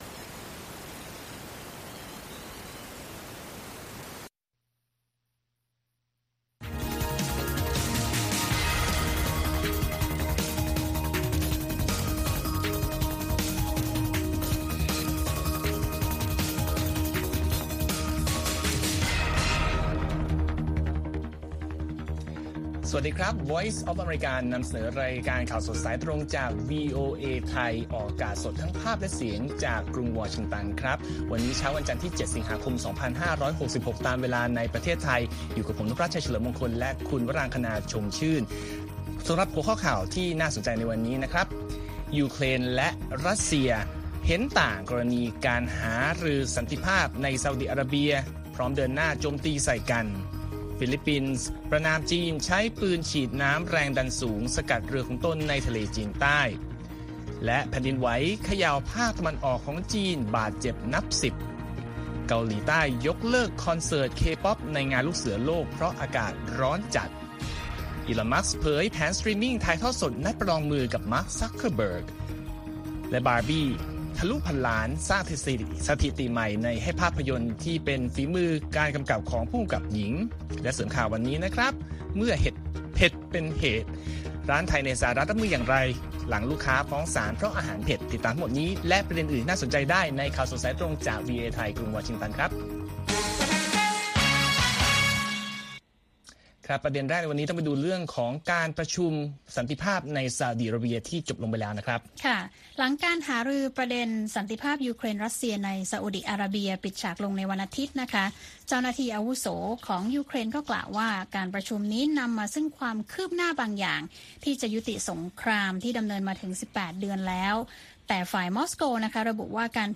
ข่าวสดสายตรงจากวีโอเอไทย 8:30–9:00 น. วันที่ 14 ส.ค. 2566